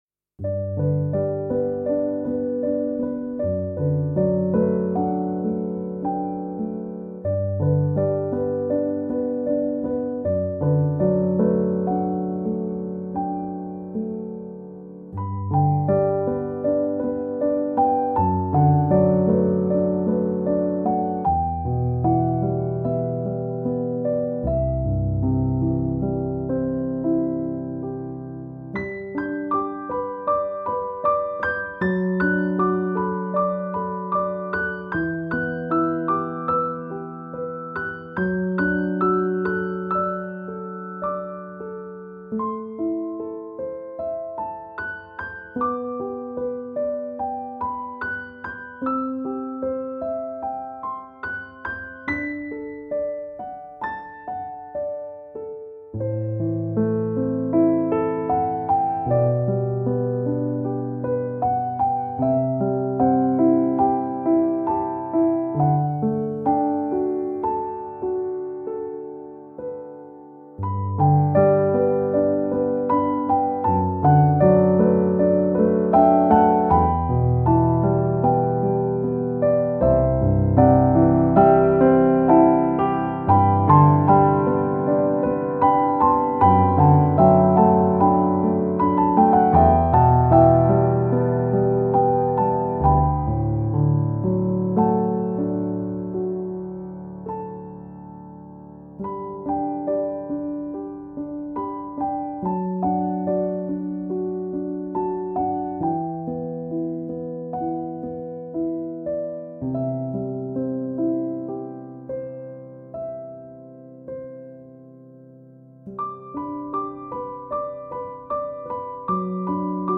Piano Solo
Voicing/Instrumentation: Piano Solo